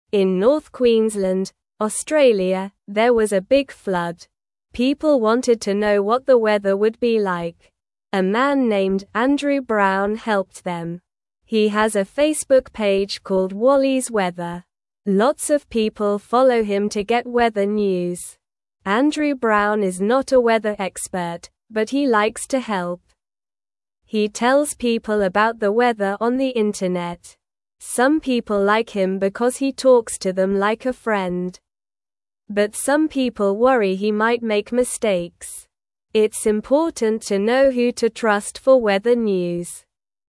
Slow
English-Newsroom-Beginner-SLOW-Reading-Helping-Friends-with-Weather-News-in-Australia.mp3